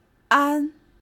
The open front unrounded vowel, or low front unrounded vowel,[1] is a type of vowel sound used in some spoken languages.
[ʔan˥] 'safe' Allophone of /a/ before /n/.[9] See Standard Chinese phonology